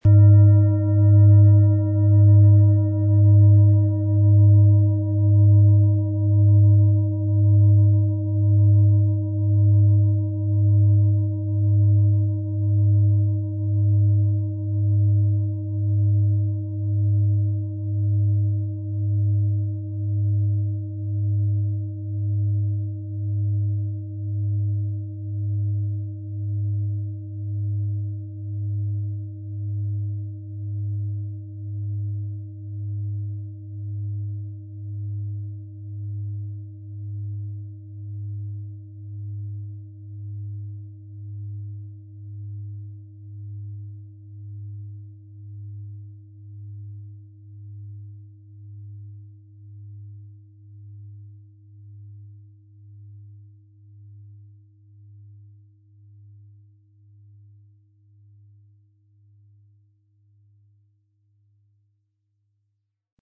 Antike Wasserstoffgamma-Klangschale – Klarheit finden und Inspiration empfangen
Ihr feiner, klarer Klang lädt dazu ein, sich mit den höheren Ebenen des Bewusstseins zu verbinden und neue Inspiration zu empfangen.
Beim Anreiben entstehen klare, lang anhaltende Töne, die den Geist fokussieren und zugleich weiten.
MaterialBronze